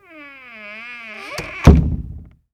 WOOD SQUEEK.WAV